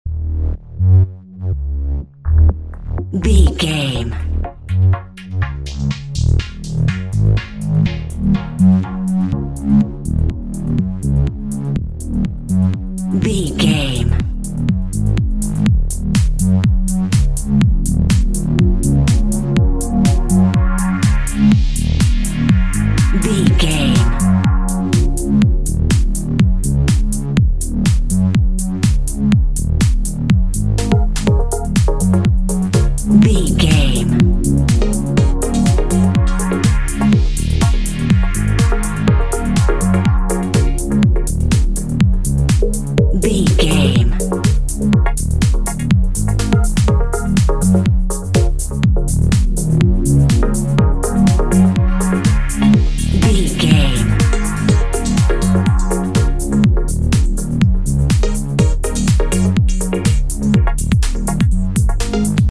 Music to Workout to.
Aeolian/Minor
E♭
groovy
dark
futuristic
drum machine
electric piano
electronic
downtempo
pads
synth bass